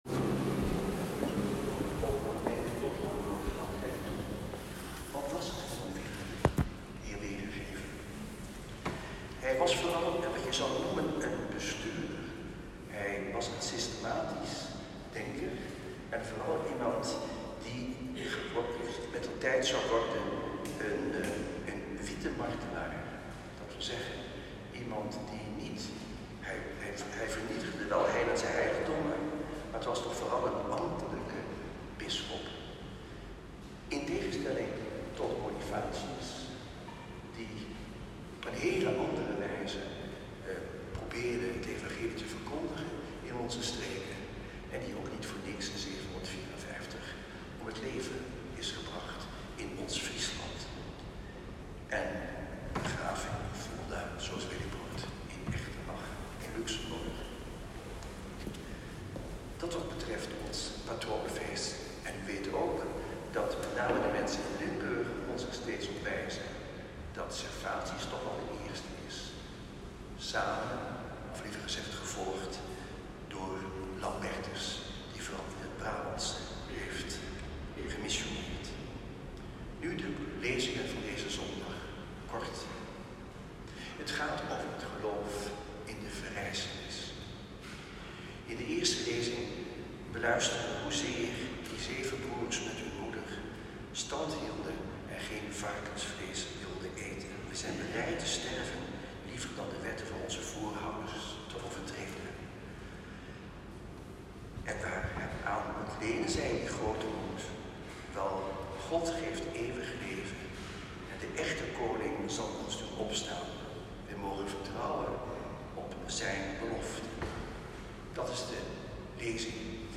Tweeëndertigste zondag door het jaar C. Celebrant Antoine Bodar.
Preek.m4a